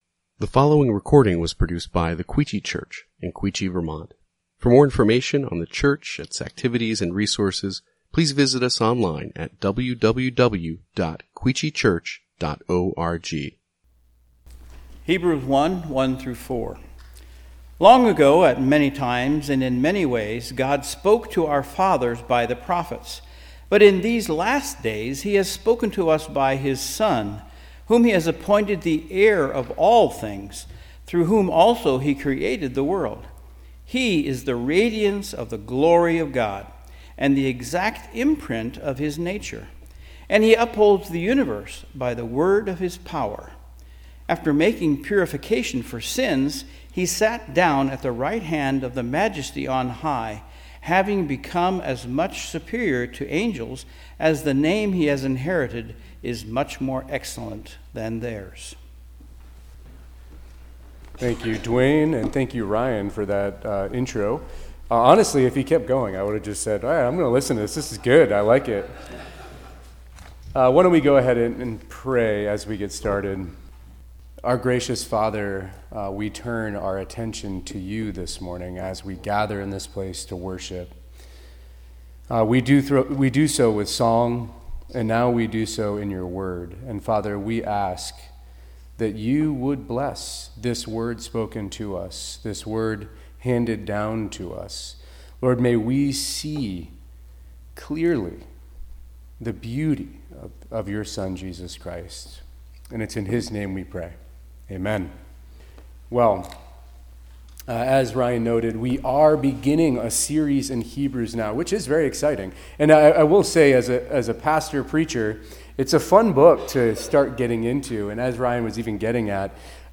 Quechee Church | Sermon Categories Hebrews Series